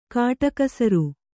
♪ kāṭakasaru